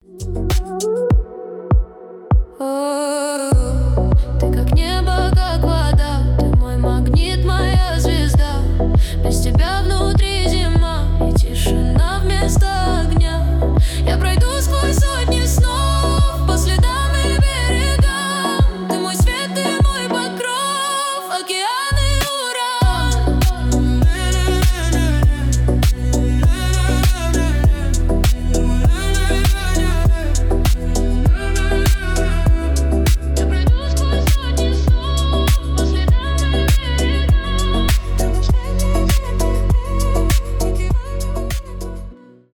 романтические
поп